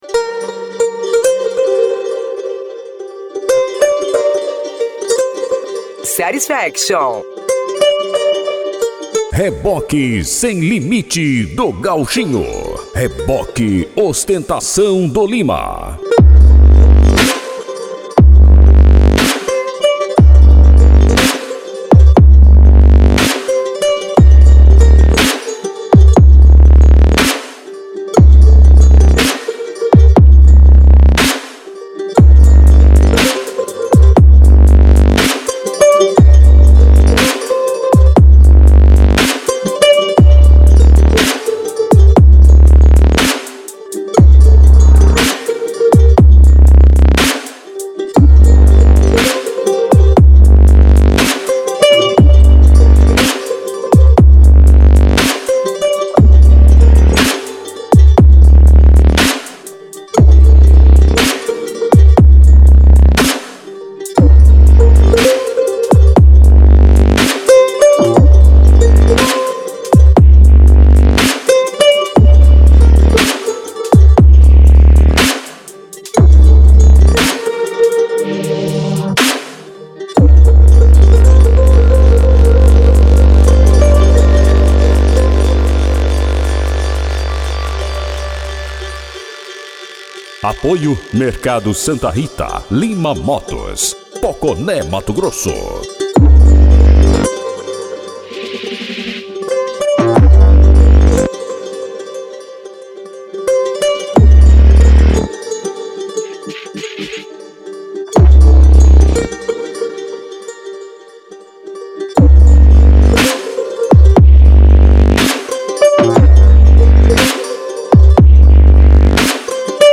Bass
Funk
Mega Funk
Remix